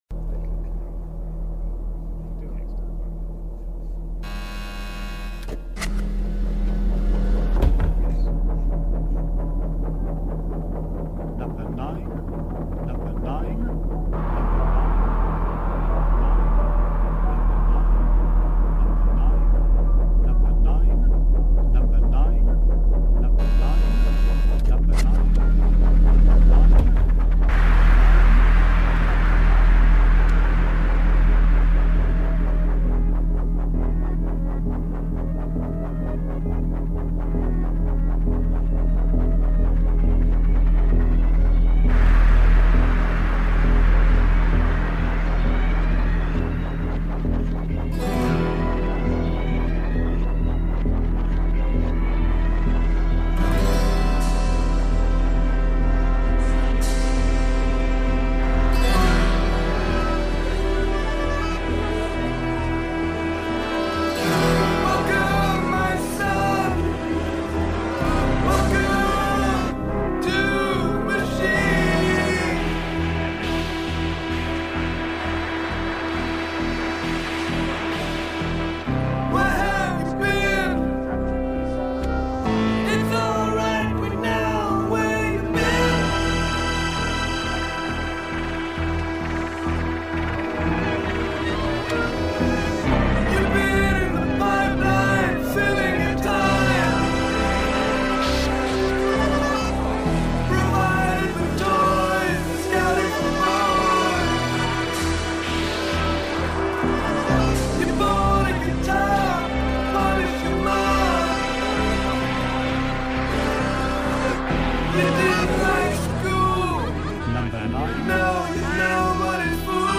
A free-form open roundtable discussion. By approaching the subject of sync through conversations, instead of the more traditional radio format, we hope to encourage insights or 'ahas' to occur more readily, both in ourselves as well as in the listener.